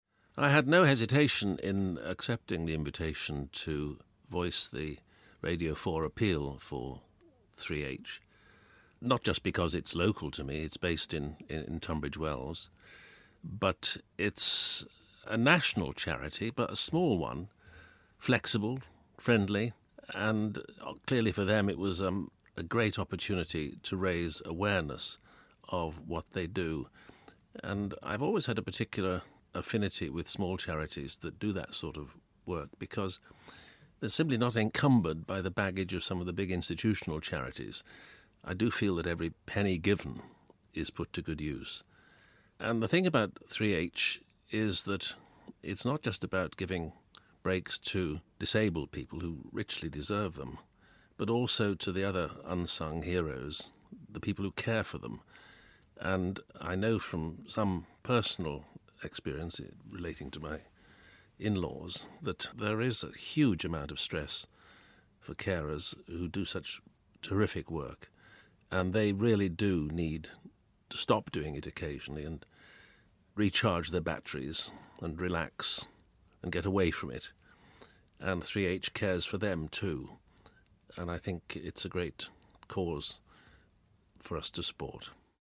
Peter Sissons presents the Radio 4 Appeal on their behalf and explains why he supports the charity.